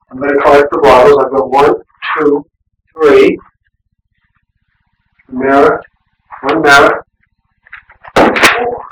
EVP's
A friendly spirits then says "Four".